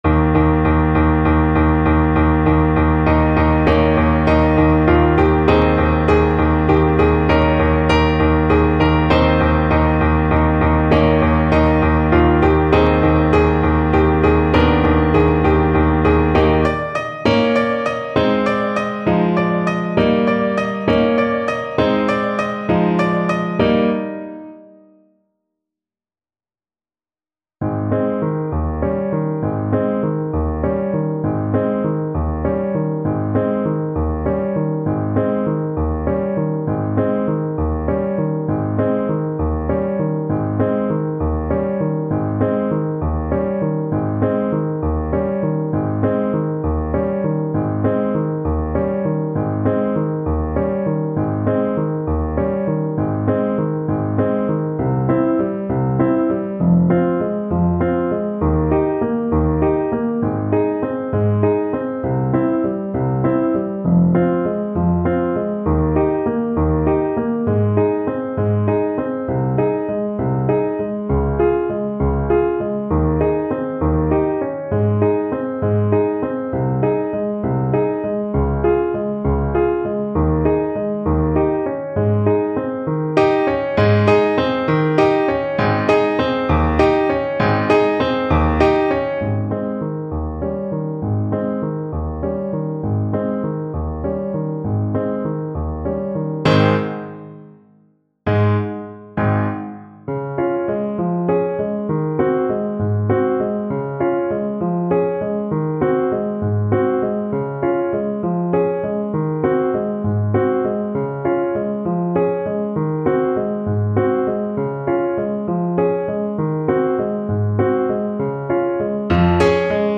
6/8 (View more 6/8 Music)
Ab major (Sounding Pitch) Bb major (Trumpet in Bb) (View more Ab major Music for Trumpet )